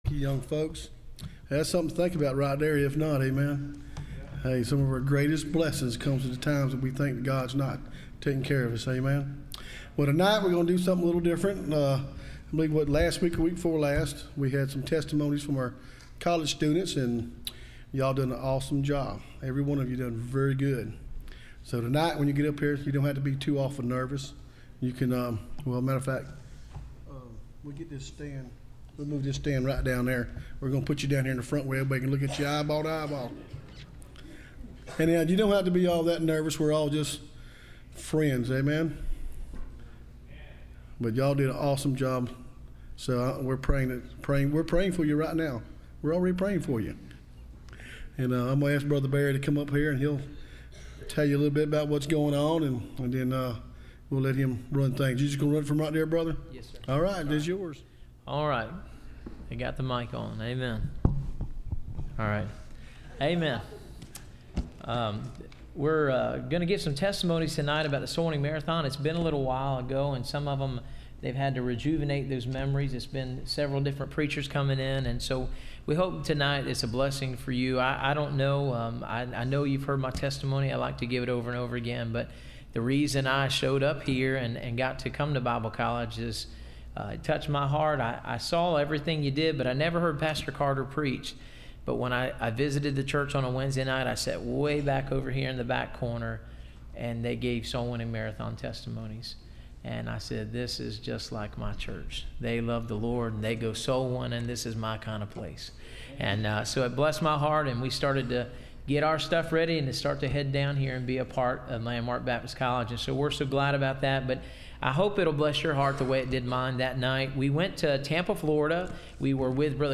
Service Type: Wednesday College